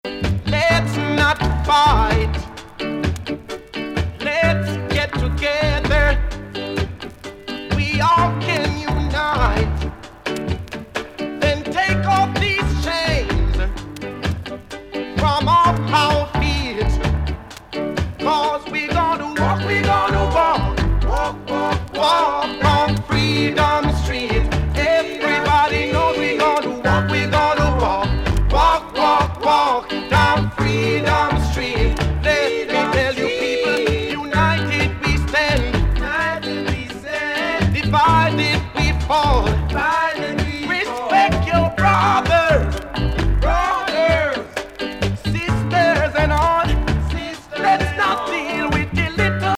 いぶし銀のVOCALが最高級の逸品!